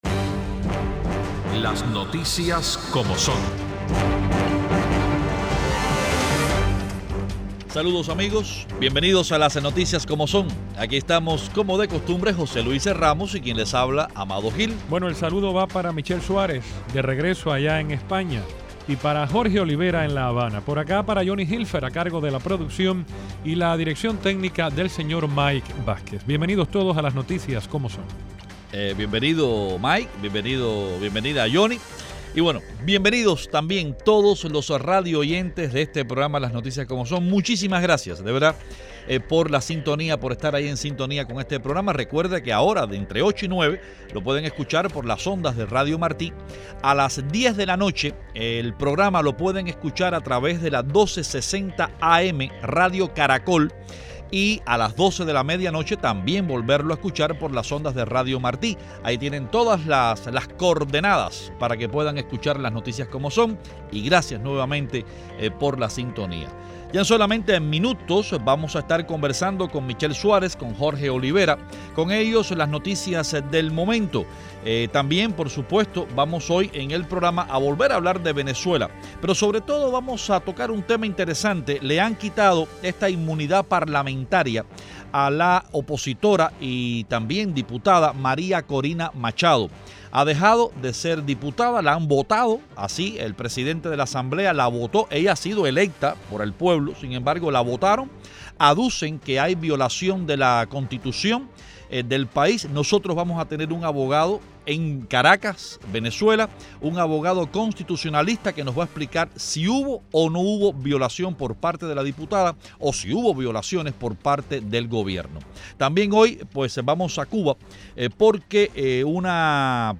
Los periodistas